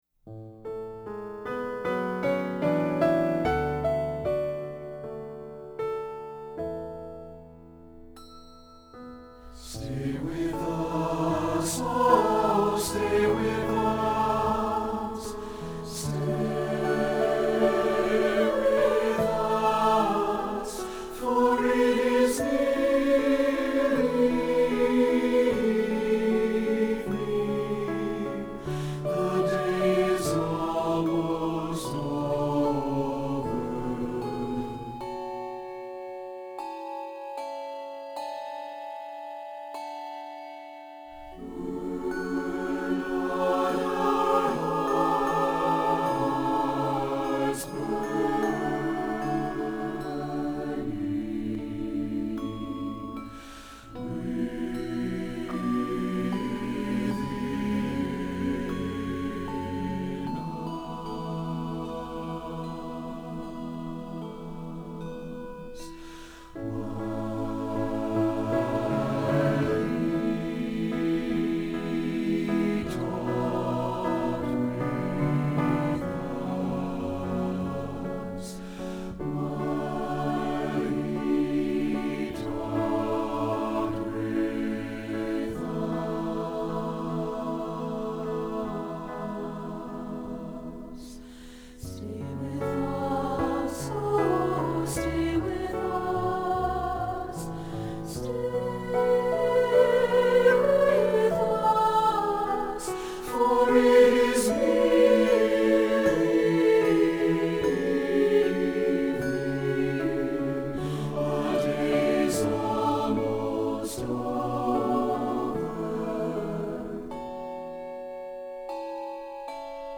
SATB, piano
Voicing: SATB